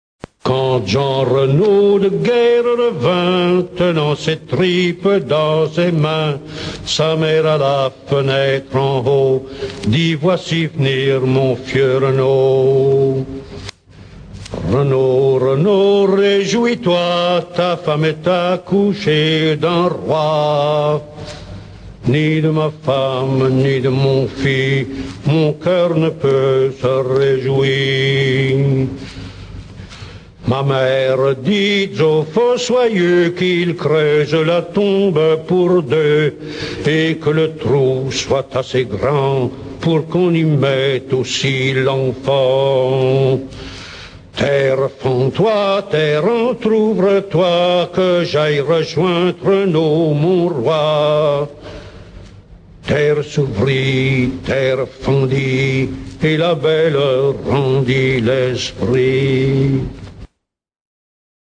Version chantée enregistrée à Namur.